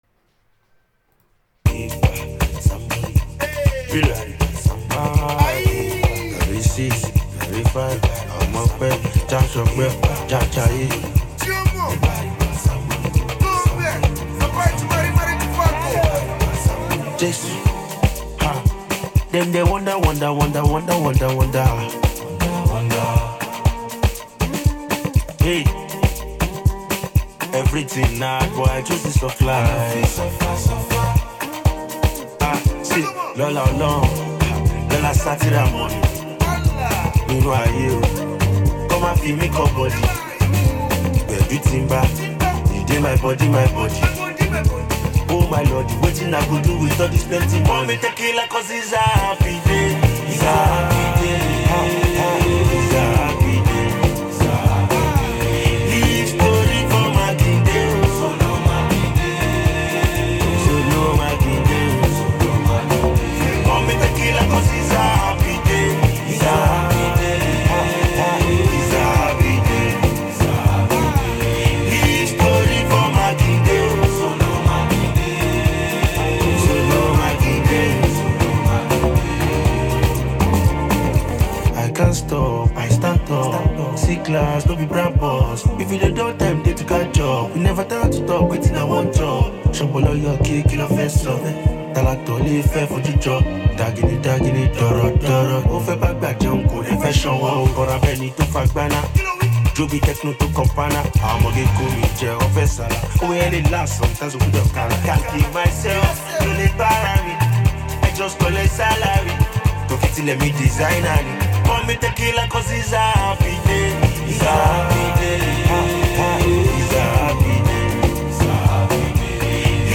Feel-Good Anthem
Known for his energetic delivery and street-savvy lyrics